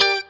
CHORD 1   AG.wav